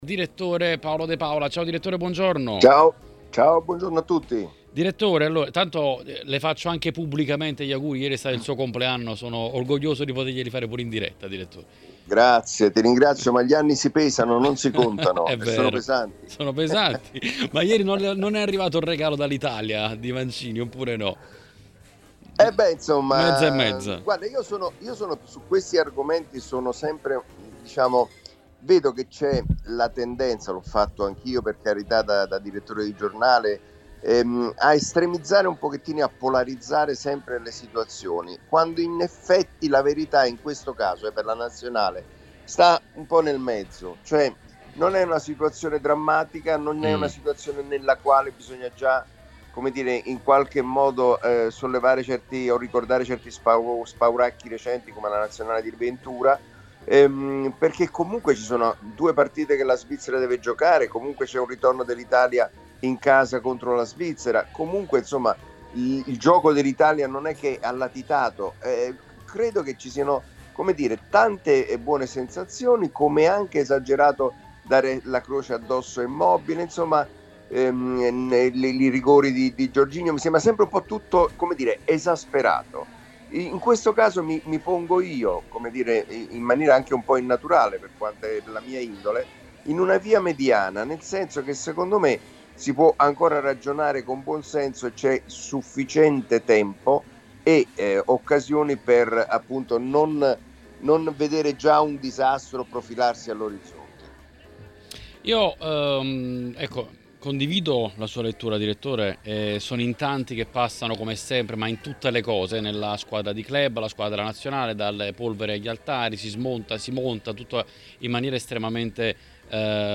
ospite nel consueto editoriale del lunedì su TMW Radio: